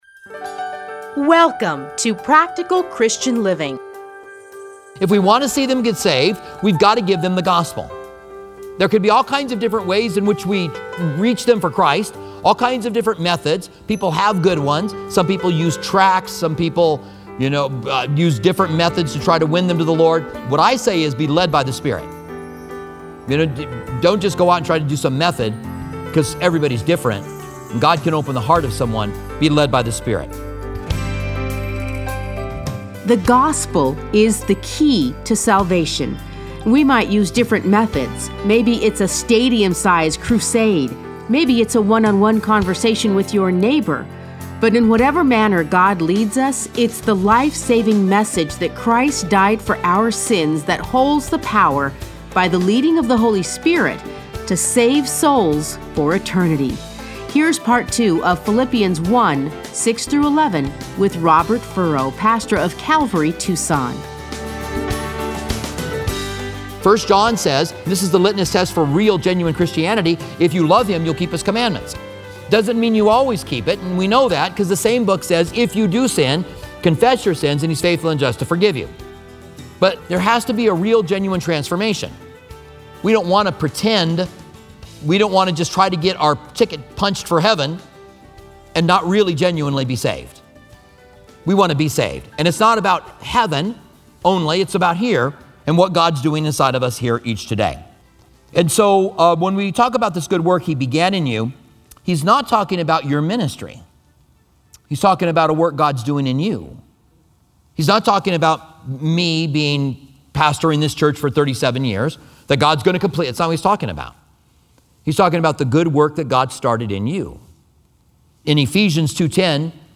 Listen to a teaching from Philippians 1:6-11 Playlists A Study in Philippians Download Audio